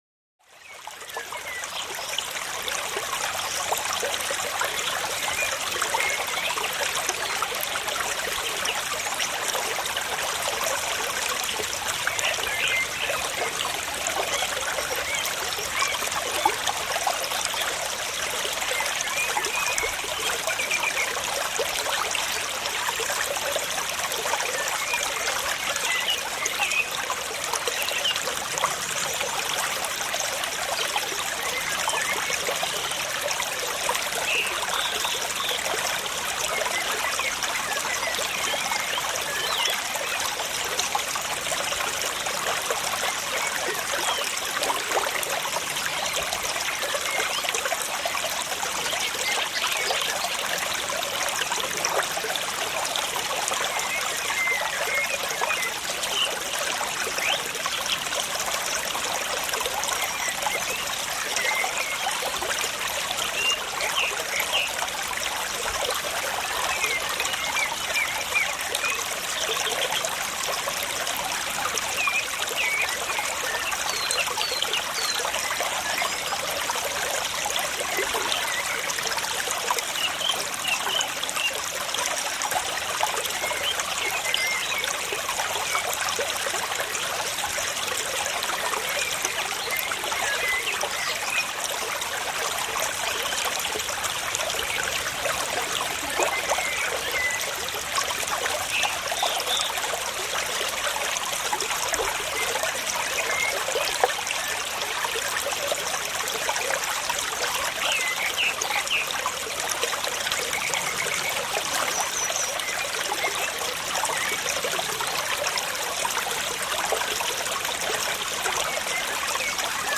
FLÜSSIGE KRISTALLE: Waldquelle-Glasperlen klingen wie Edelsteine